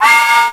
TRAINWSL.WAV